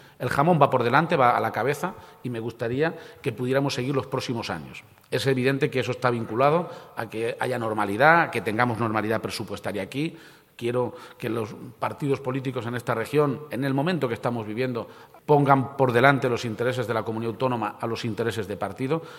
«Hay muchas cosas en juego, desde la sanidad, la educación, los servicios sociales, la dependencia a muchas otras», ha subrayado García-Page durante su intervención en la inauguración del XI Congreso Mundial del Jamón Curado.